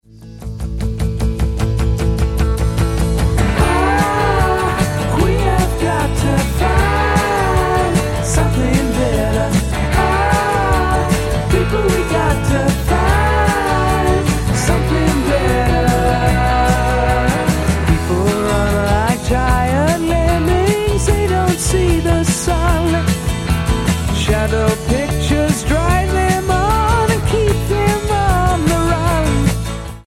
bass, vocals
drums, percussion
guitar, vocals, solina, piano
Album Notes: Recorded at Can-Base Studios, Vancouver, Canada